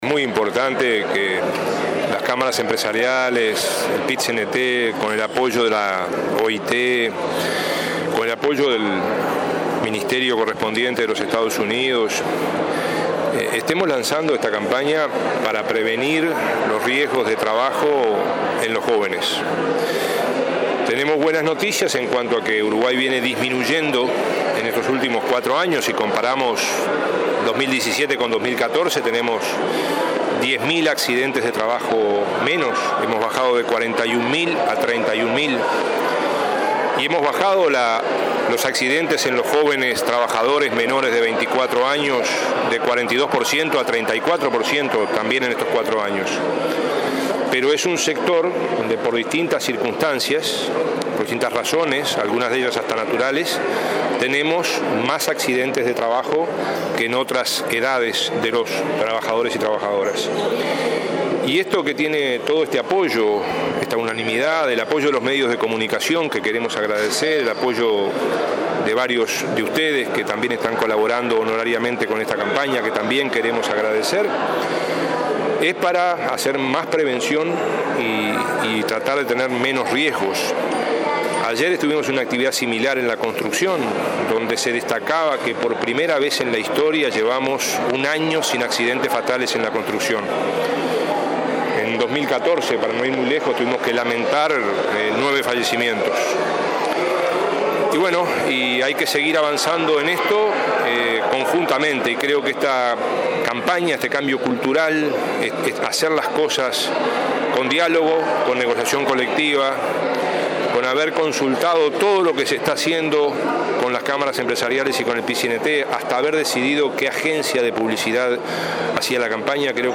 En 2017 se registraron 10.000 accidentes de trabajo menos que en 2014 y se bajaron los accidentes en los jóvenes menores de 24 años, de 42 % a 34 %, en estos cuatro años, explicó el ministro de Trabajo, Ernesto Murro, en la presentación de la campaña para prevenir riesgos de trabajo en jóvenes, lanzada por el ministerio, los privados y trabajadores.”Hay que avanzar en este cambio cultural de hacer las cosas con diálogo”, dijo.